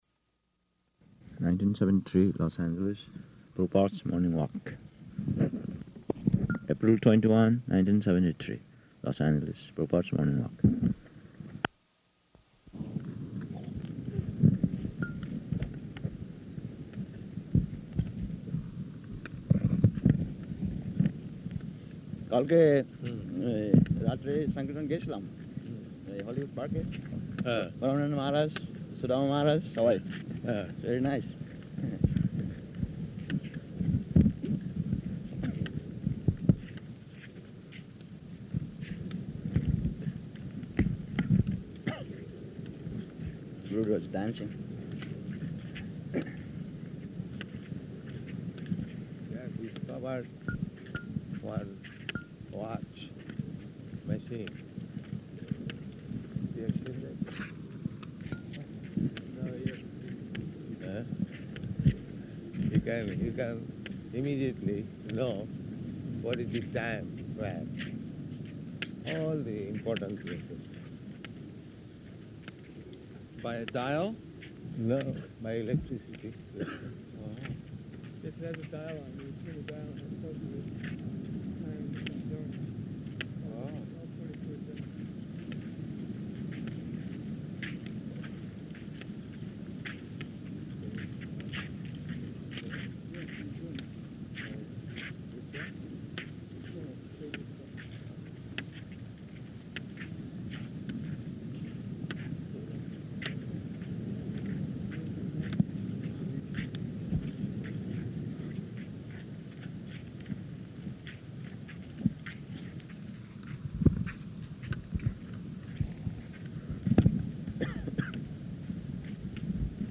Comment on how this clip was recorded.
-- Type: Walk Dated: April 21st 1973 Location: Los Angeles Audio file